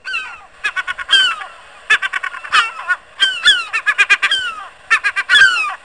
01_mouette.mp3